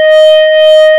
n = 6
Notice that we have not simply slided the spectrum up, but stretched it.